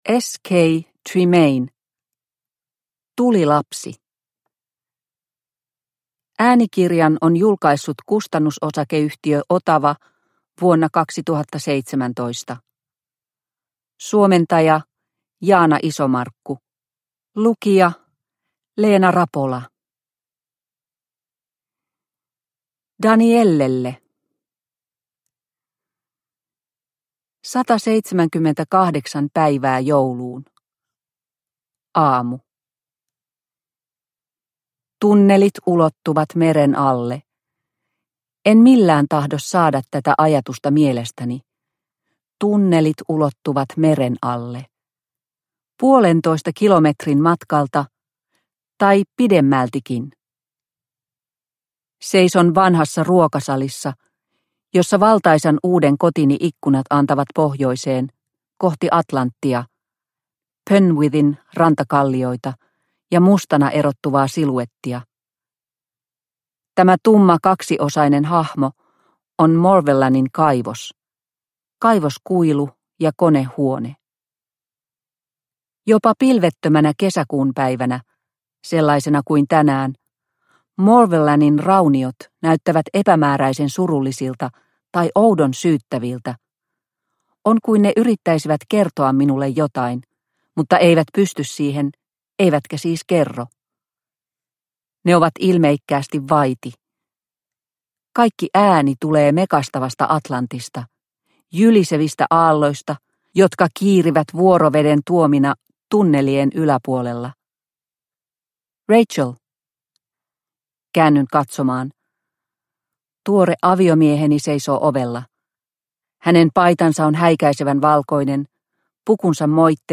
Tulilapsi – Ljudbok – Laddas ner